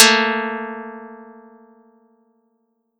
Audacity_pluck_1_13.wav